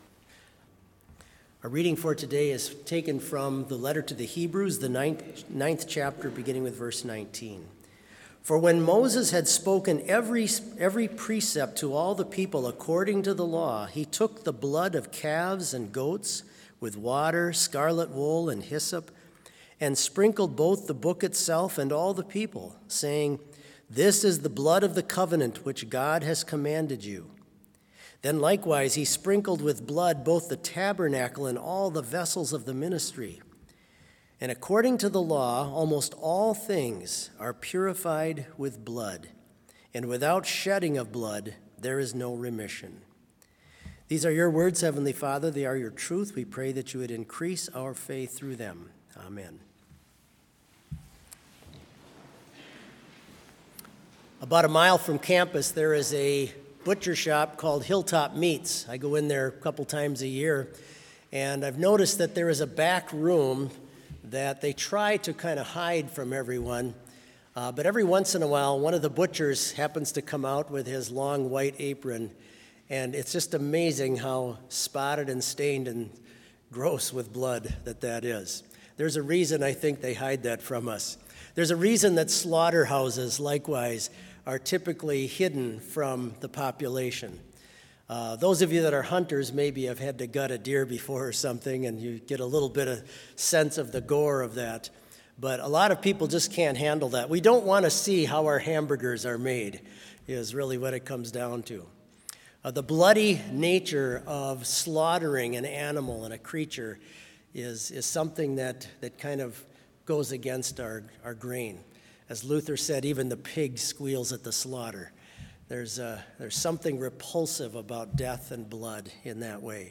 Complete service audio for Chapel - April 4, 2022
vv. 1, soloist; vv. 2, all